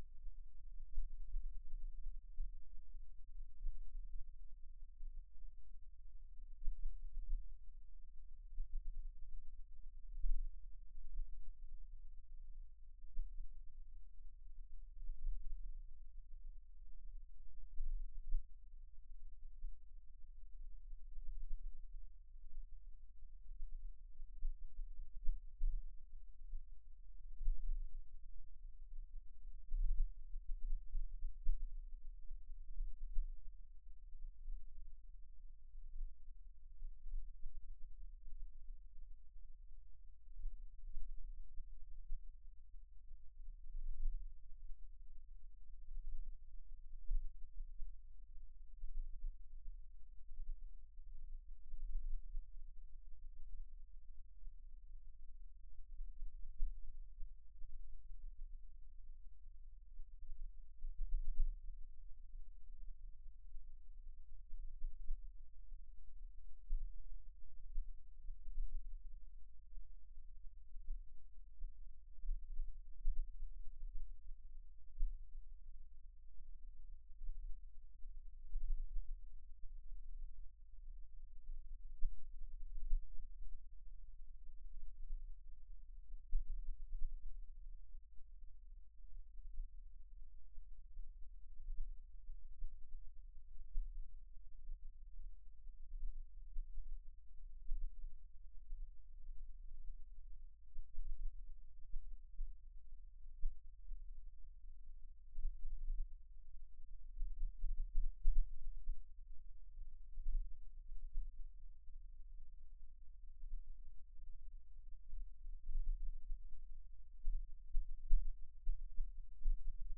Monitoring St. 04 - ROMAGNA OBSERVATORY From Sogliano al Rubicone(FC), N Italy.
Schumann resonances on left side. Top and bottom graph show the entire file, the middle spectrogram shows 7 minutes detail with earthquake on center, followed a microphonic effect generated by coil shaked by seismic wave.